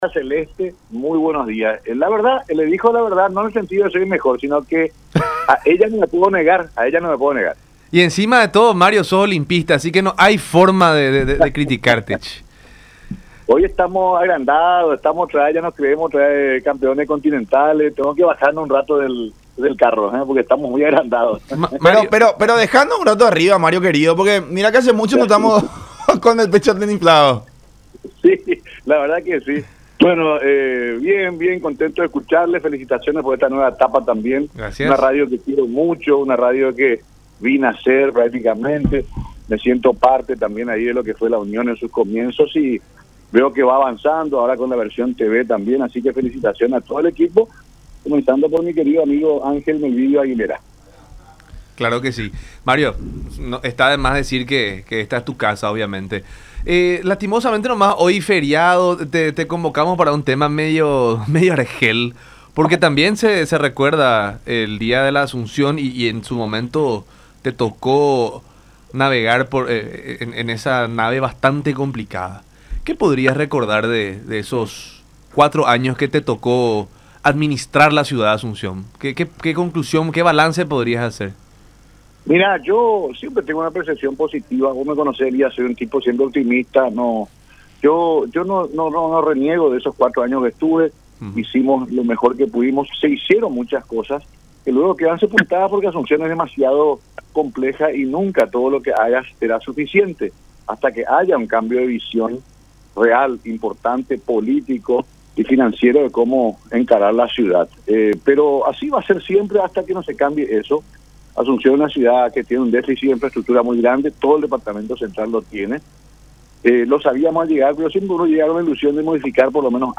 Puede ganar cualquiera que tenga buenas intenciones, pero después termina bastante rechazado por más obras que pueda hacer”, dijo en conversación con La Unión Hace La Fuerza por Unión TV y radio La Unión.